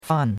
fan4.mp3